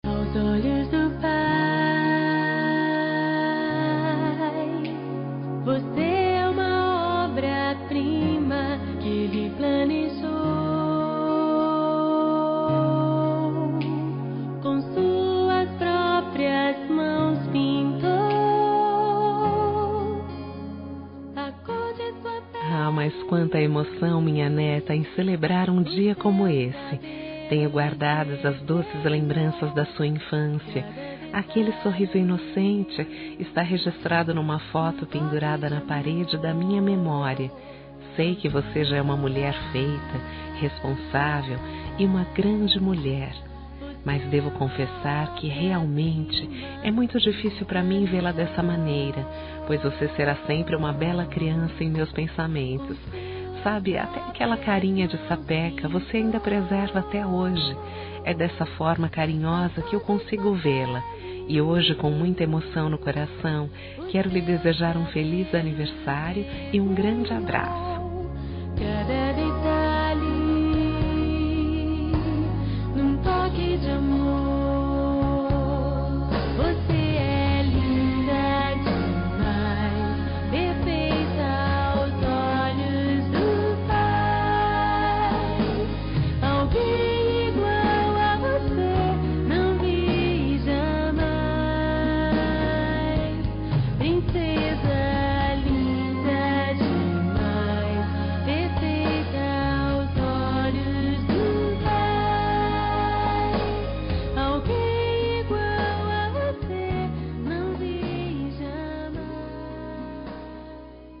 Aniversário de Neta – Voz Feminina – Cód: 131021